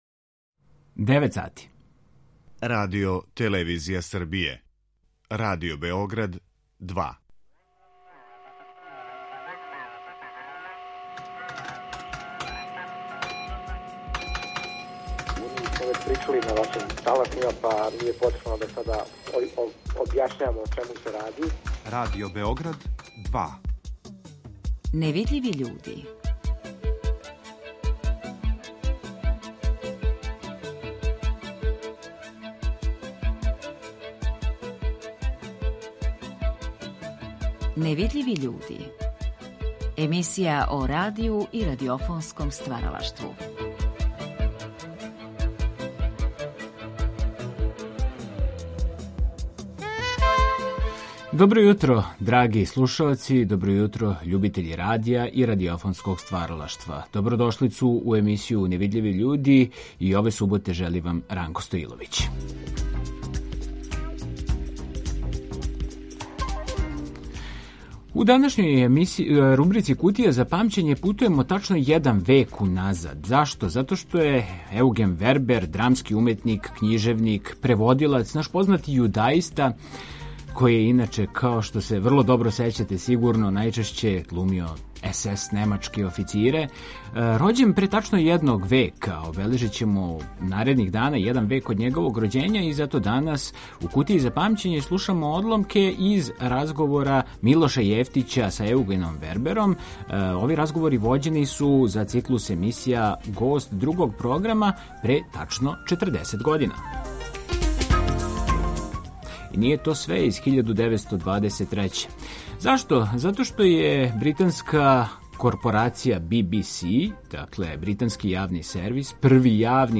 У редовној рубрици „Кутија за памћење" емитујемо одломке из разговора